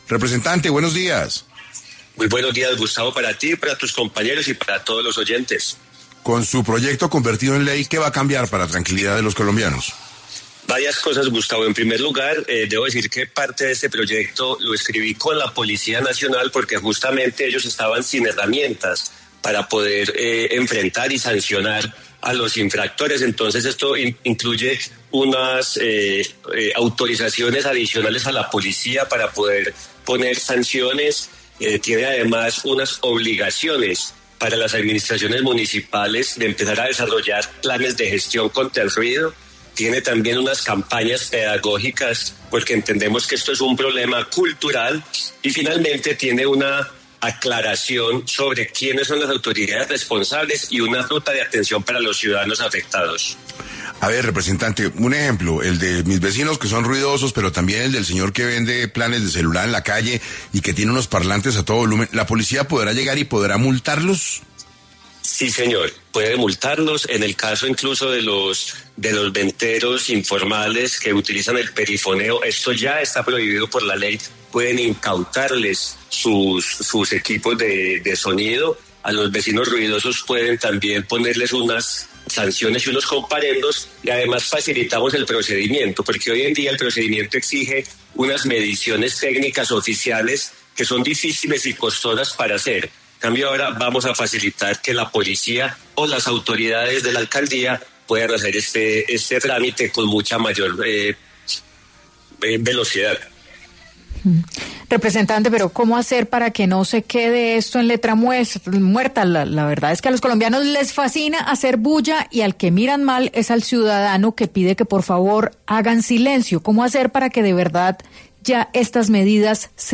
En 6AM de Caracol Radio estuvo el representante ante la Cámara, Daniel Carvalho, para hablar sobre un proyecto de ley respecto al ruido y cuáles son las sanciones para quienes la incumplan.